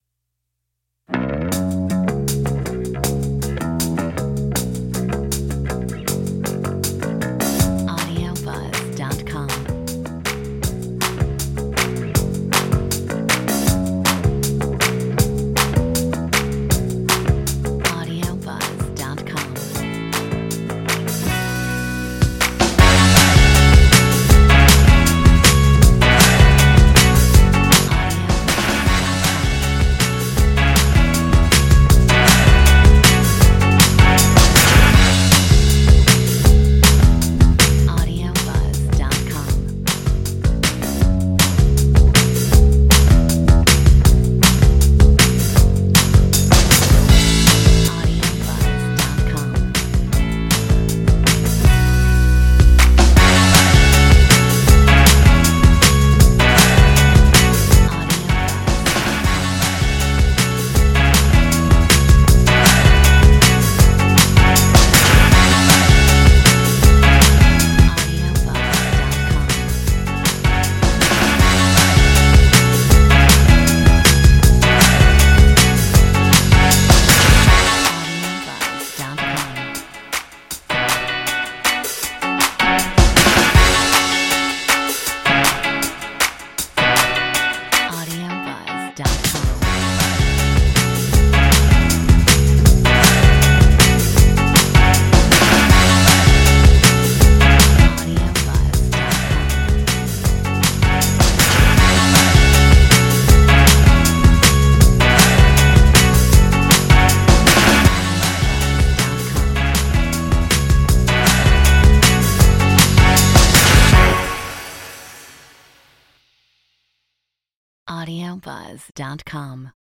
Metronome 160